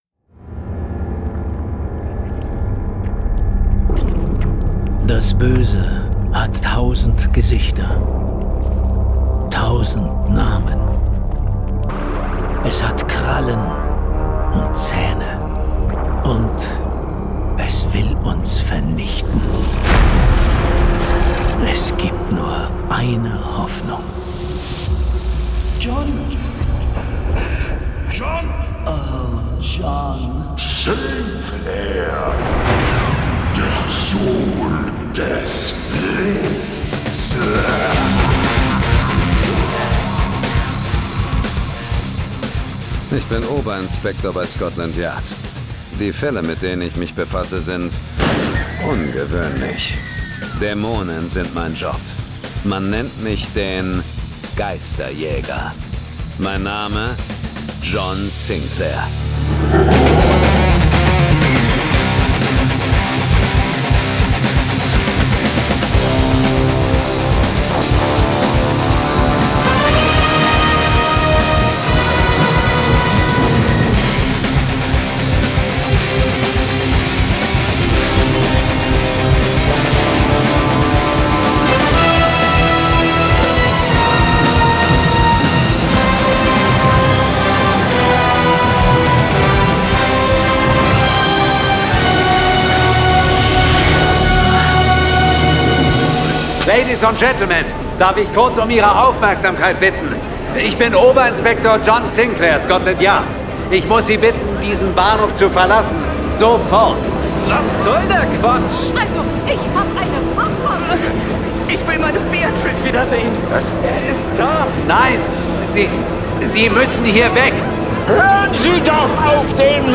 John Sinclair - Folge 190 Zombie-Zug. Hörspiel.